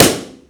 BalloonPop
balloon burst pop sound effect free sound royalty free Sound Effects